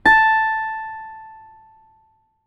ZITHER A 3.wav